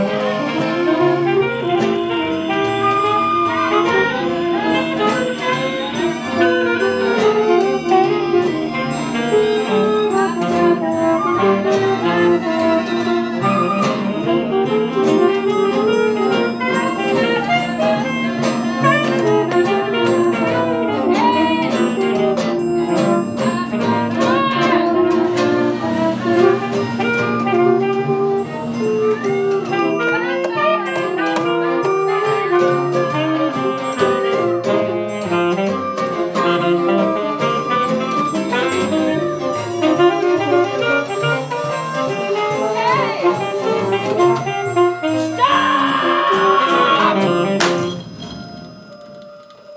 im Foyer die Klänge der Nacht herauf.
Sie schnarcht und kracht
Sie jault und bellt
Sie swingt und jazzt